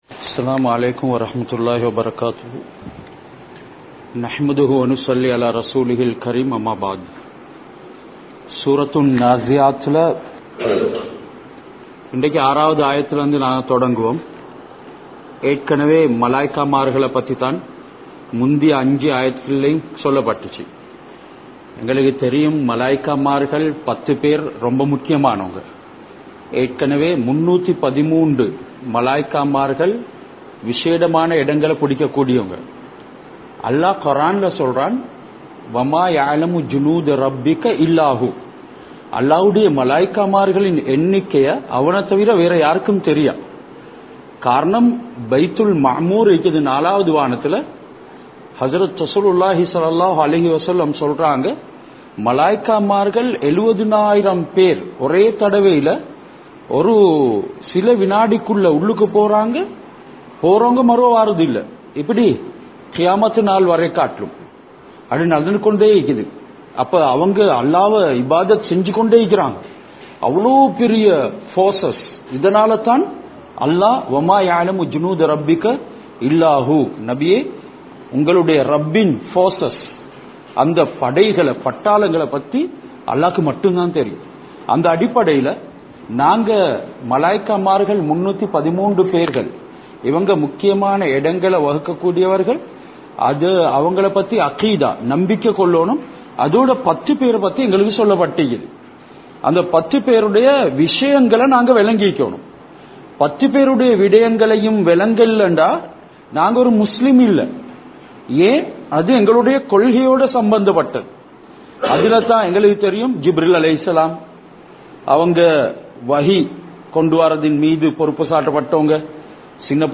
Surah An Naaziyath(Thafseer Versus 6-12) | Audio Bayans | All Ceylon Muslim Youth Community | Addalaichenai